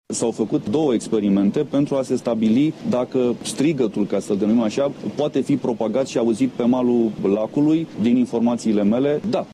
Declaraţia a fost făcută astăzi de procurorul general al României, Tiberiu Niţu: